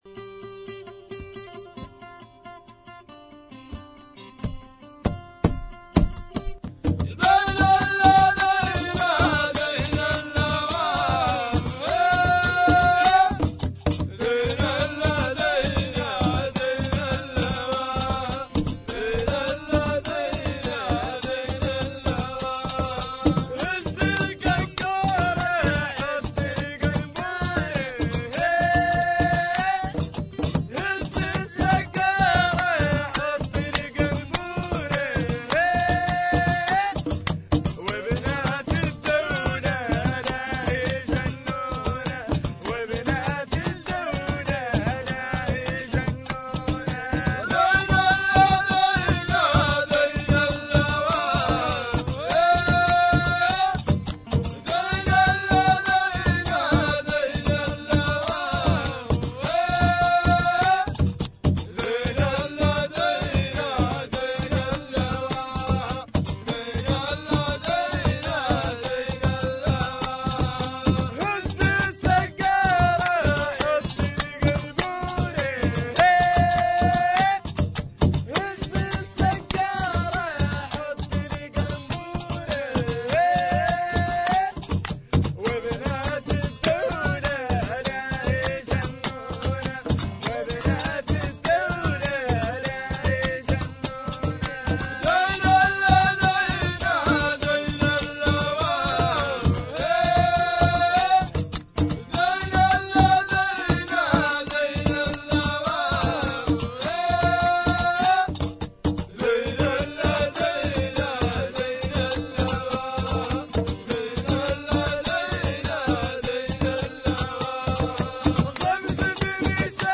Chants traditionnels sahraouis
2 - Rythme CHAMERA